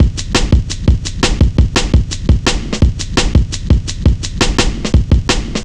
Index of /90_sSampleCDs/Zero-G - Total Drum Bass/Drumloops - 3/track 58 (170bpm)